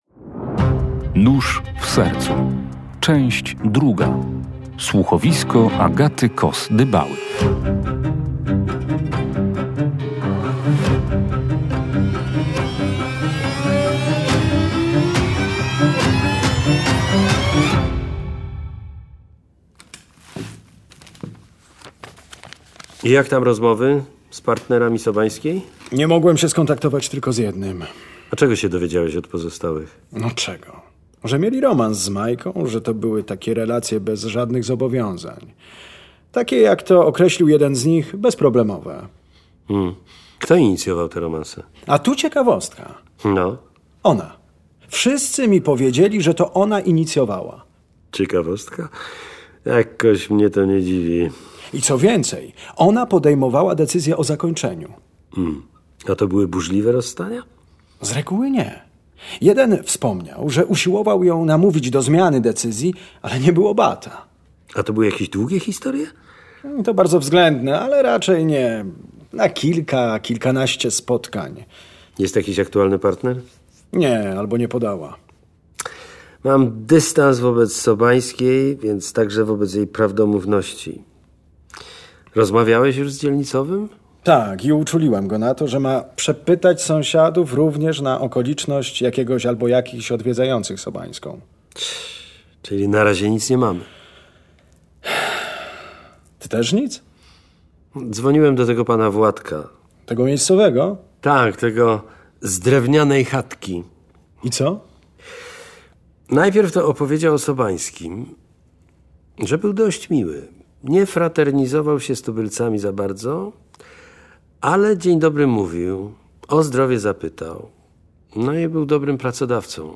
Słuchowisko przeznaczone jest dla osób dorosłych.